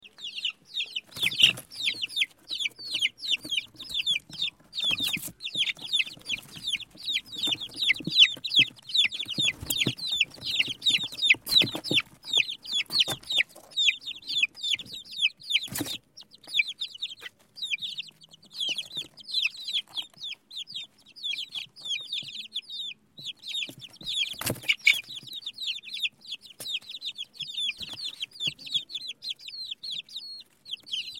دانلود آهنگ صدای جوجه مرغ 1 از افکت صوتی انسان و موجودات زنده
جلوه های صوتی